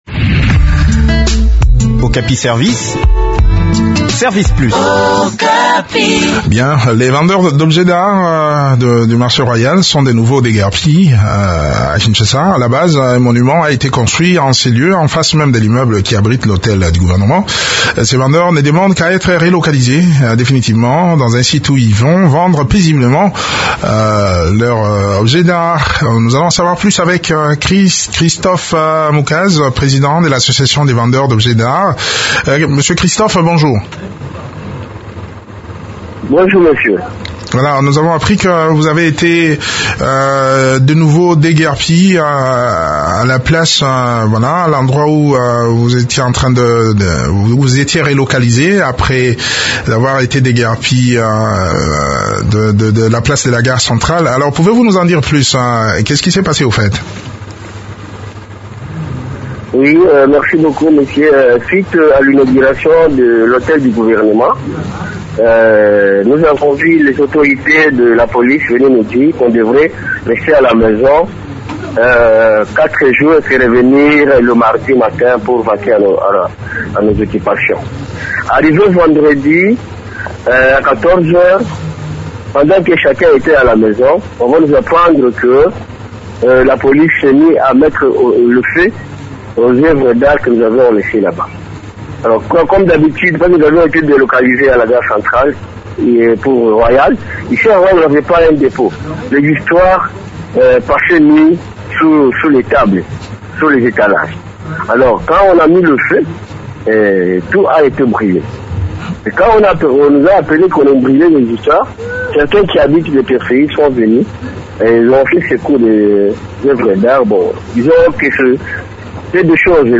Le point de la situation sur terrain dans cet entretien